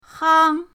hang1.mp3